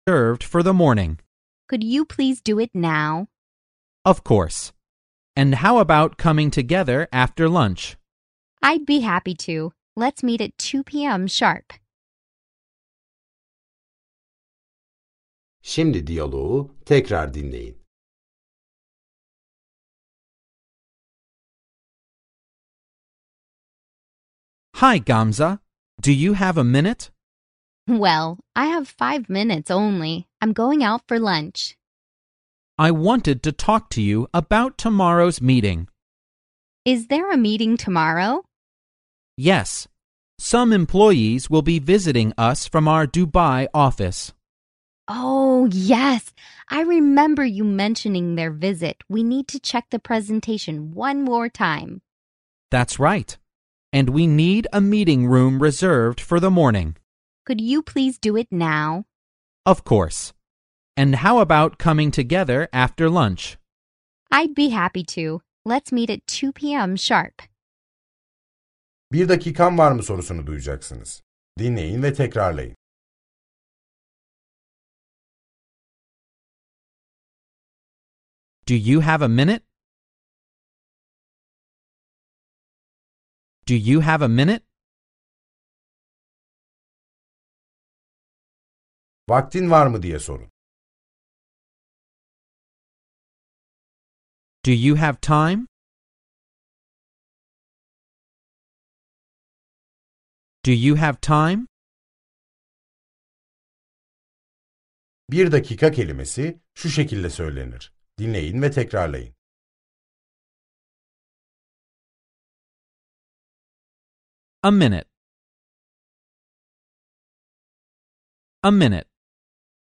Dersler boyunca sizi yönlendiren bir kişisel eğitmeniniz olacak. Ana dili İngilizce olan iki kişi de sürekli diyalog halinde olacaklar. Yönlendirmeniniz size söz verdikçe gerekli tekrarları yapın ve sorulan sorulara cevap verin.